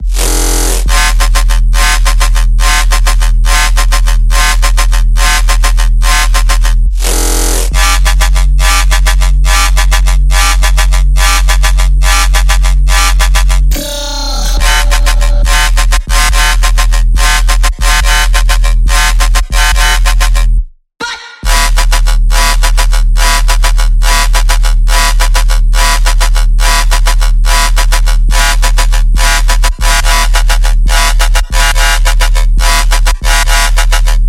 深入的Riddim Bass 4
Tag: 140 bpm Dubstep Loops Bass Wobble Loops 5.77 MB wav Key : F FL Studio